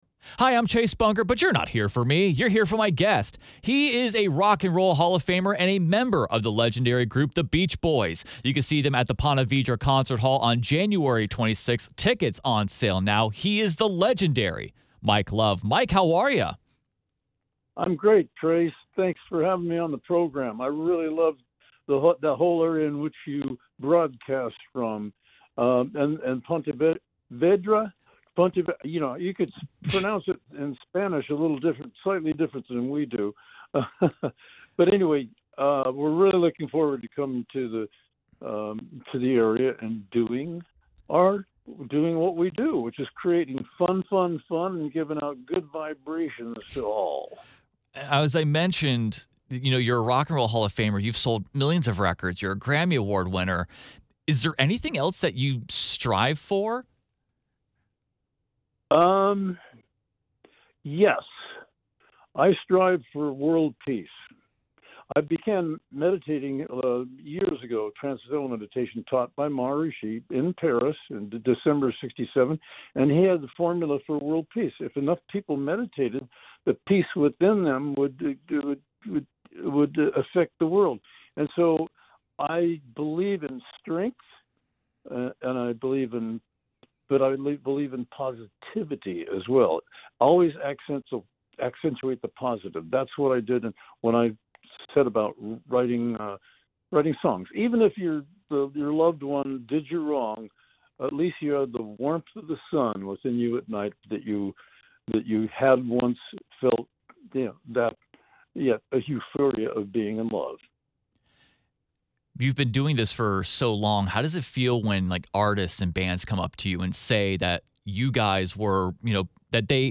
You can hear Mike Love tell some of his favorite memories with Brian Wilson and what it’s like to be called an influence for bands in the full interview.